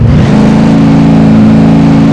f350_revdown.wav